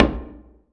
Index of /90_sSampleCDs/AKAI S6000 CD-ROM - Volume 5/Brazil/SURDO
SI SURDO 3.WAV